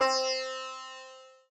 sitar_c.ogg